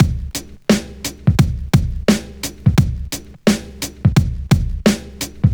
• 87 Bpm '00s Drum Groove C# Key.wav
Free drum loop - kick tuned to the C# note. Loudest frequency: 1080Hz